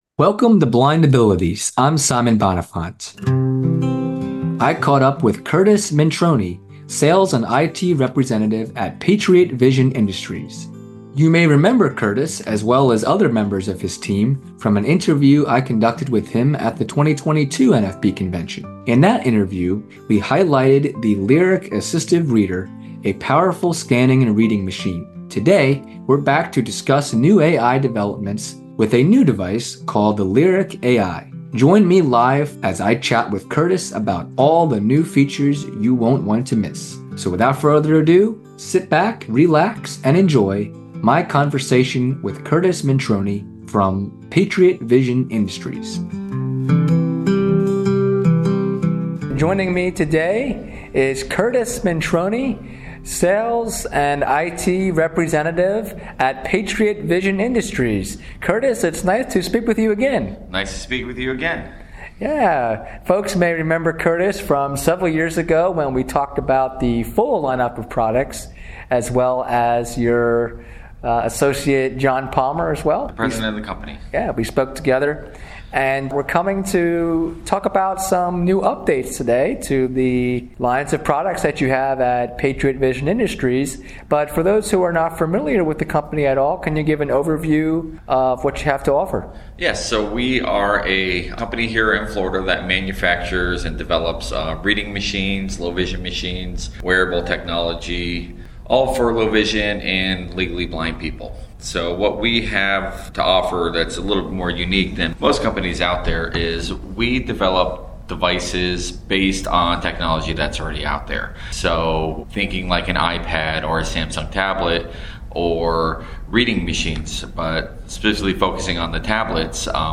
at the Nation Federation for the Blind 2024